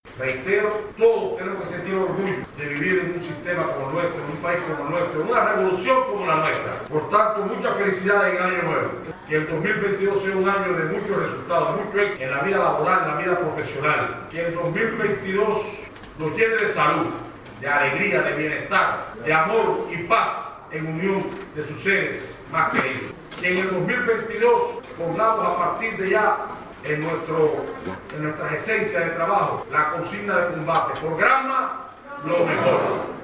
El mensaje lo envió Federico Hernández Hernández, primer secretario del Partido en Granma, en el acto por el aniversario 63 del triunfo de la Revolución Cubana, el 1 de enero de 2022, en el que fue reconocido el trabajo del personal que asegura el trabajo de ambos organismos, y el de la escuela del Partido Desembarco del Granma.
Palabras-de-Federico-Hernández-Hernández-1.mp3